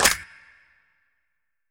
Snare (9).wav